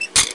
Gateclosing Sound Effect
Download a high-quality gateclosing sound effect.
gateclosing.mp3